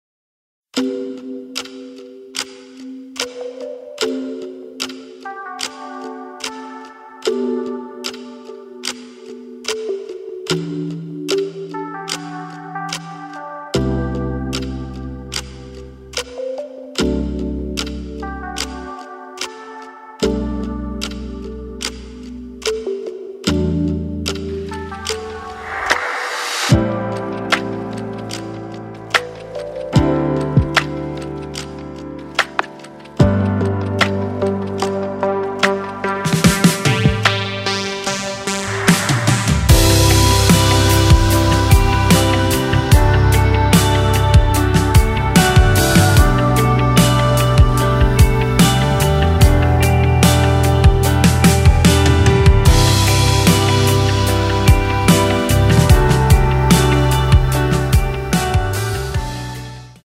伴奏信息
歌曲调式：G大调